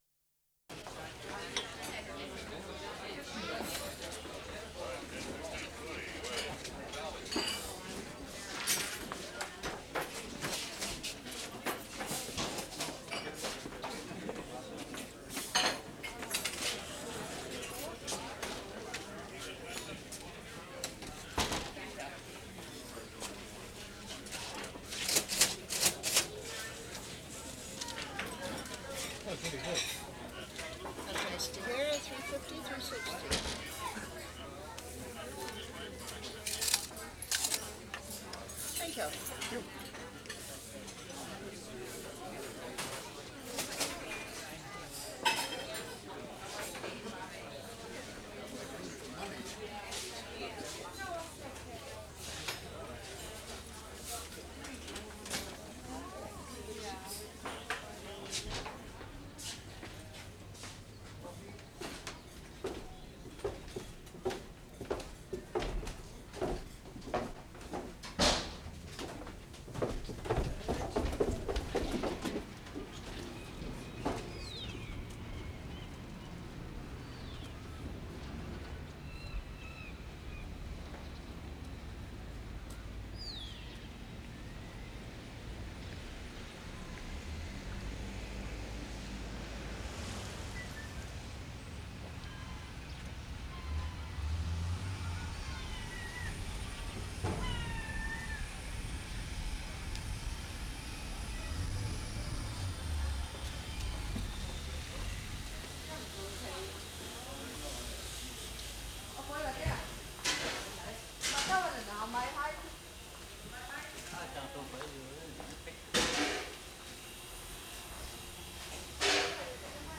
VANCOUVER HARBOUR March 22, 1973
FISHERMAN'S WHARF 7'55"
3. Starts in restaurant, then leaving and wandering about on the packing and loading wharf. Various mechanical noises, and one old electric radio, playing CJJC. This is not as coherent a sequence as take 1.
0'25" cash register, pay for meal, leave restaurant.
*2'30" banging on oil drums.
2'40" into another packing place, radio playing, passing water sounds on concrete.
3'30" close-up radio and static, water sounds, women's voices.
5'20" loading noises, dumping crabs into cart.
7'10" cart rattles off, whirring sound briefly from elsewhere, men's voices, seagulls screaming in background.